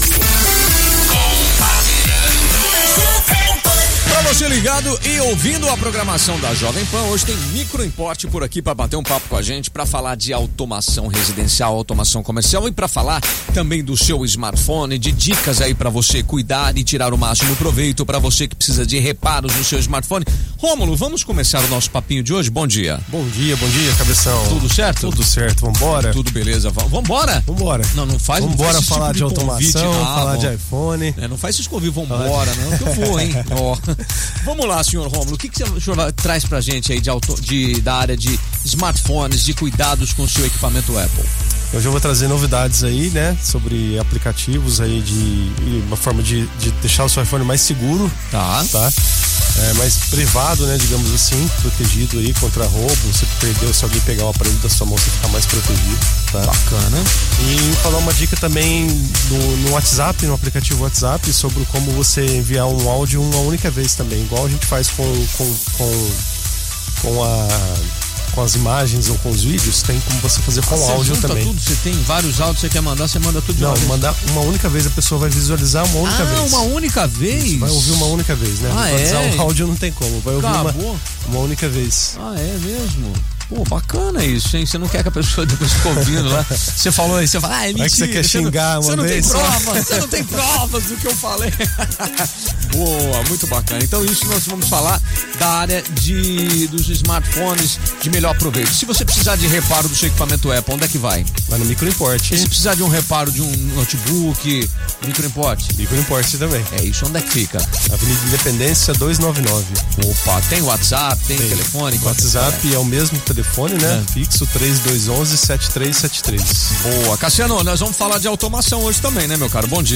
No episódio de estreia do nosso podcast na Jovem Pan, abrimos o microfone para um bate-papo leve, informativo e cheio de inspiração sobre automação residencial e empresarial — tecnologias que estão transformando não só casas e escritórios, mas também vidas.